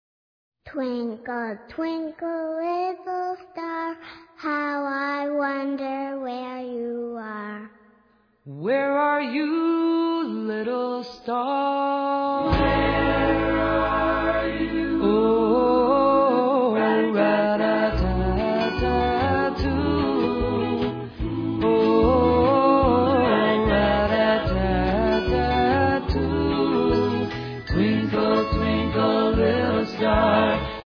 excellent rock 'n' roll with wacky skits